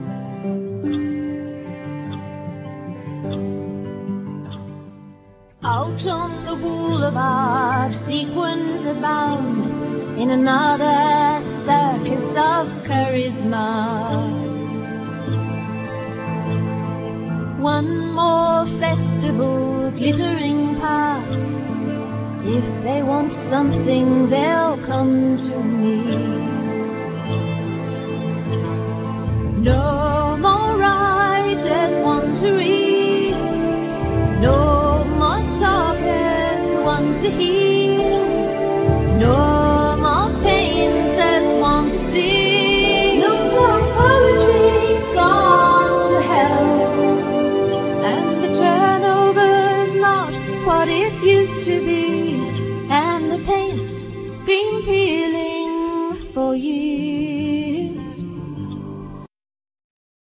Vocal,Mandolin,Firesticks,Bells,Chimes,Keyboards
Keyboard,Bass,Tablas,Angel harp,Effects
Percussions
Oboe